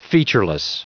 Prononciation du mot featureless en anglais (fichier audio)